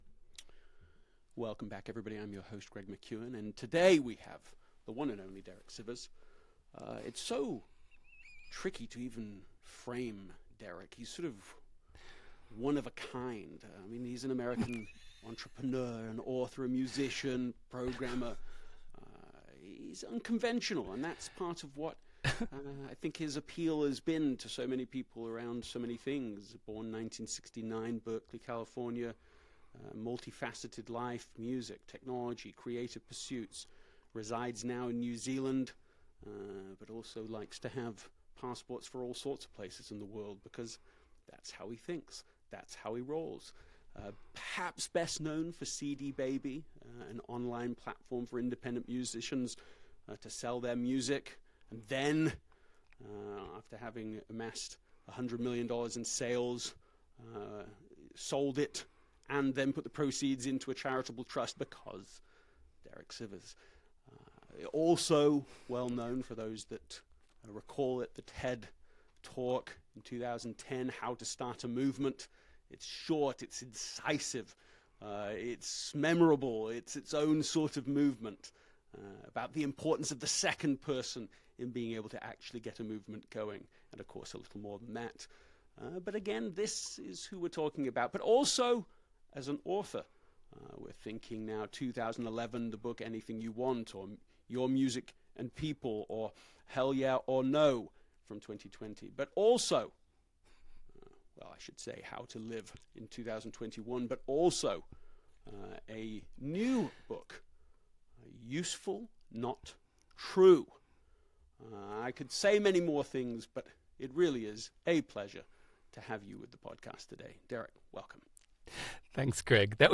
Greg McKeown interview by Greg McKeown | Derek Sivers